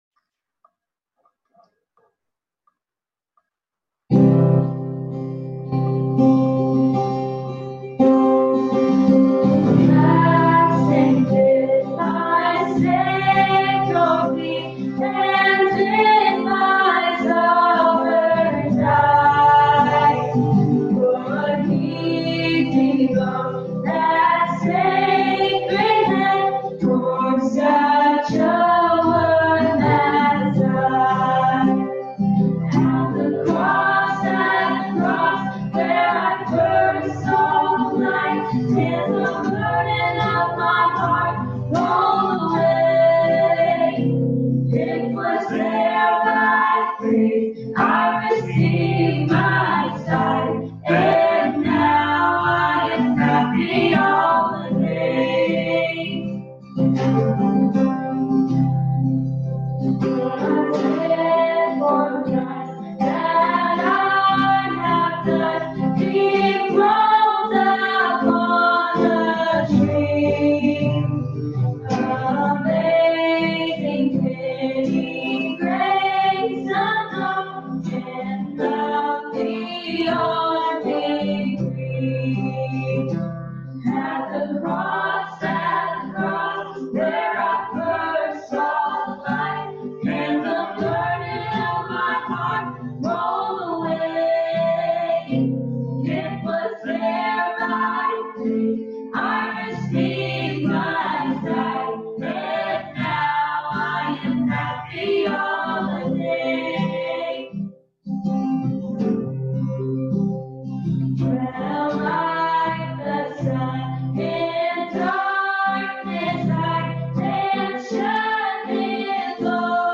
He Is Risen-A.M. Service